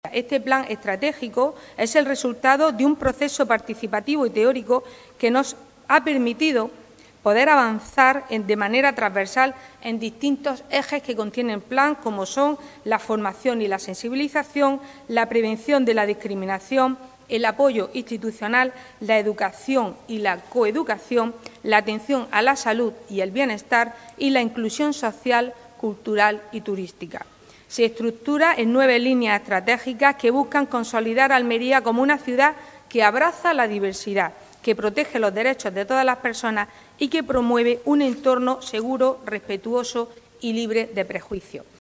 La concejala Paola Laynez presenta este instrumento estratégico de actuación y las actividades con motivo del Día Internacional del Orgullo, arropada por diferentes colectivos y entidades
Con estas palabras Paola Laynez, concejala de Familia, Inclusión e Igualdad, define la relevancia del I Plan Municipal para la Garantía de los Derechos de las Personas LGTBI en la ciudad de Almería (2025-2029), presentado hoy, en rueda de prensa, arropada por los colectivos y entidades que participarán en el próximo Día Internacional del Orgullo LGTBI.